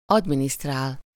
Ääntäminen
IPA : /ədˈmɪnɪstɚ/